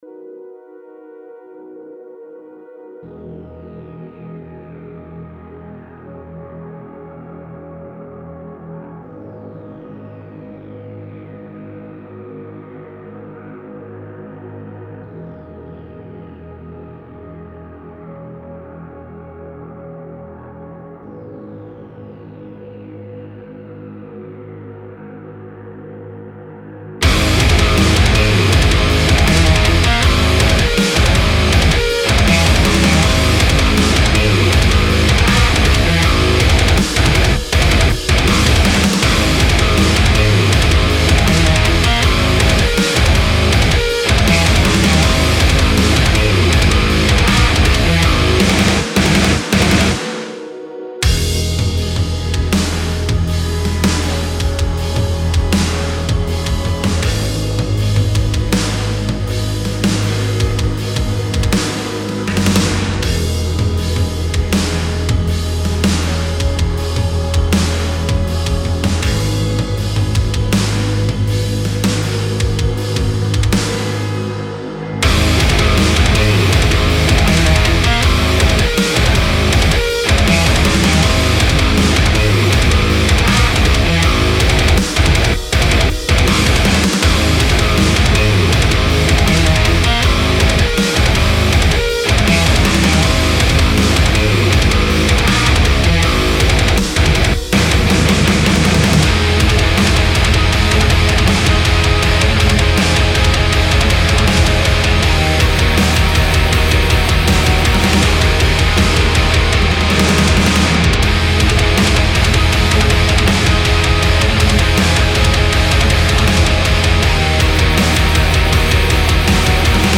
Random Cymabl nki's > Chango snare and kick > Morgan C Toms
Parallel comp > Clipper
Toms are raw.